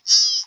AV_deer_short.wav